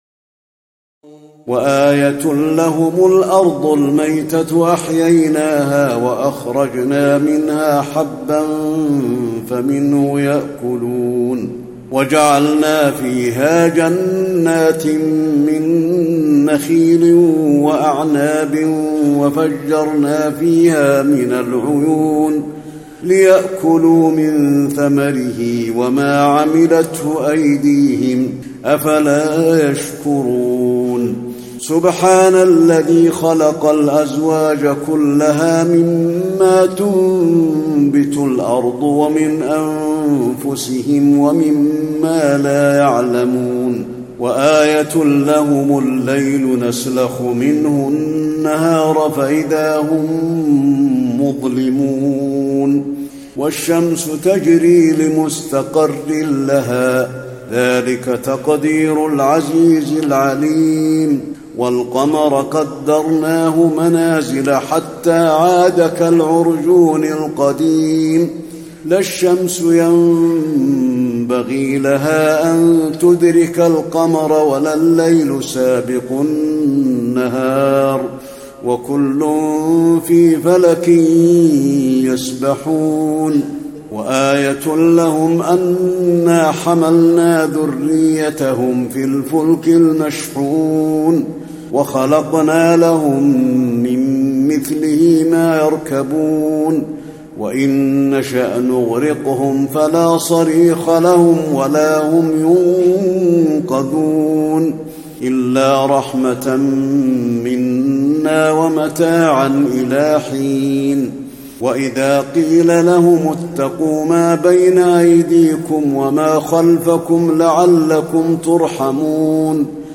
تراويح ليلة 22 رمضان 1435هـ من سور يس (33-83) والصافات (1-157) Taraweeh 22 st night Ramadan 1435H from Surah Yaseen and As-Saaffaat > تراويح الحرم النبوي عام 1435 🕌 > التراويح - تلاوات الحرمين